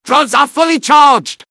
medic_autochargeready03.mp3